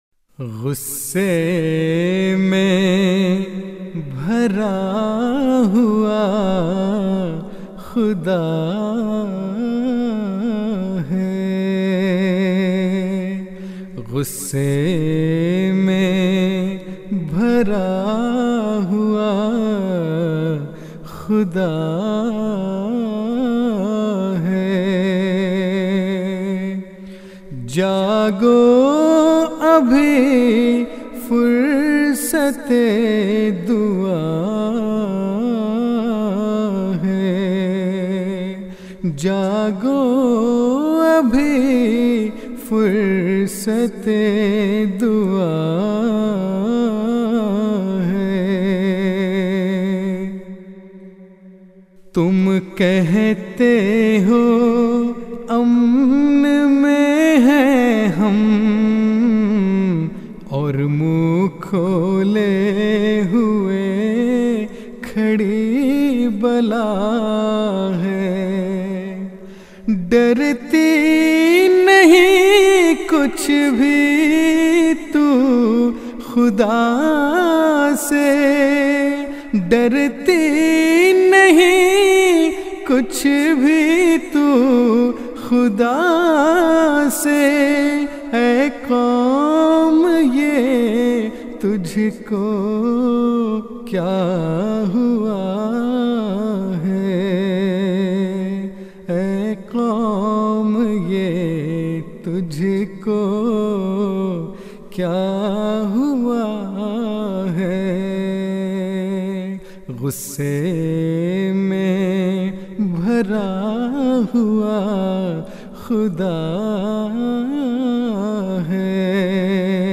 Urdu Poems
جلسہ سالانہ جرمنی ۲۰۱۶ء Jalsa Salana Germany 2016